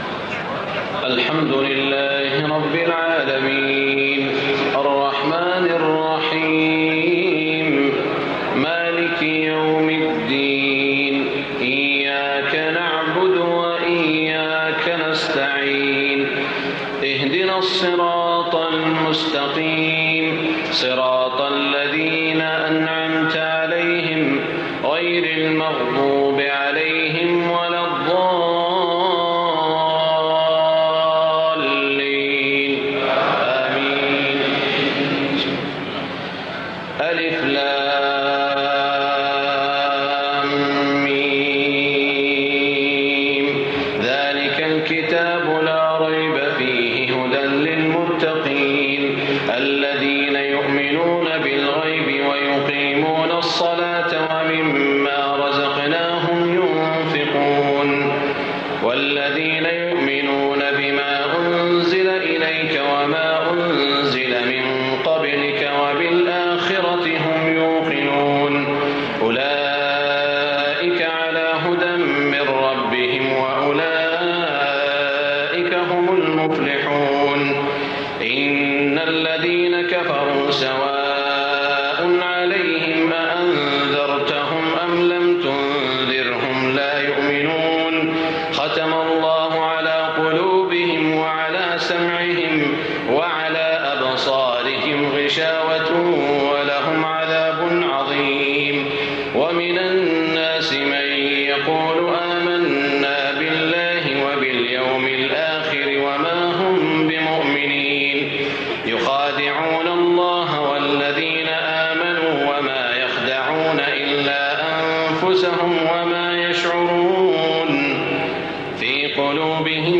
تهجد ليلة 21 رمضان 1425هـ من سورة البقرة (1-91) Tahajjud 21 st night Ramadan 1425H from Surah Al-Baqara > تراويح الحرم المكي عام 1425 🕋 > التراويح - تلاوات الحرمين